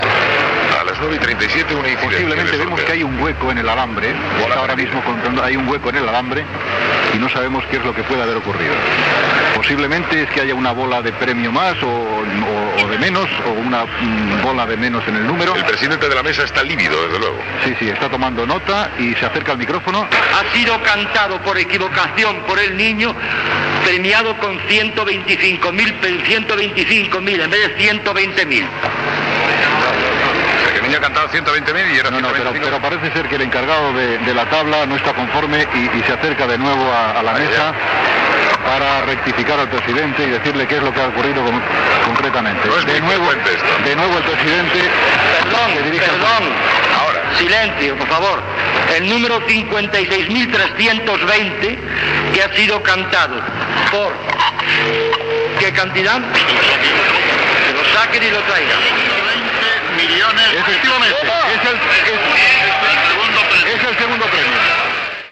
Transmissió de la Rifa de Nadal.
Info-entreteniment